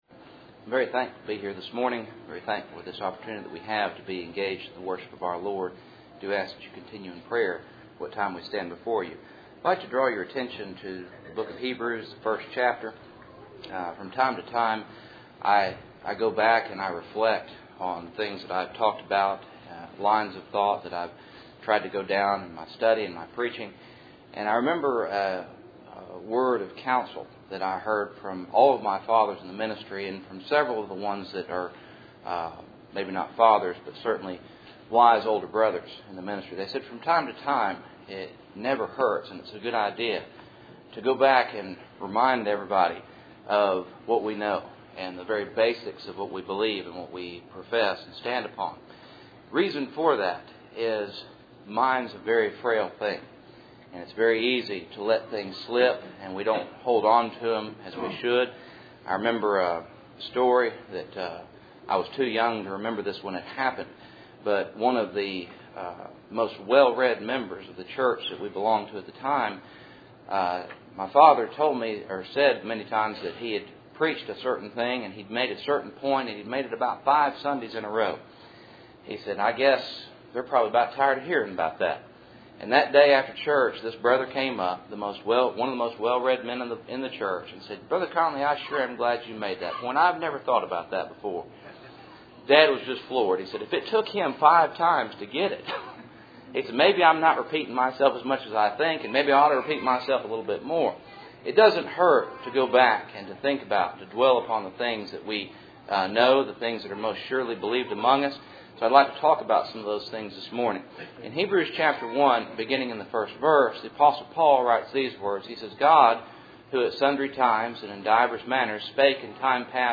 Hebrews 1:4 Service Type: Cool Springs PBC Sunday Morning %todo_render% « Proper Order Handling the Ark Joseph